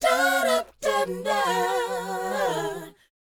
DOWOP C#DU.wav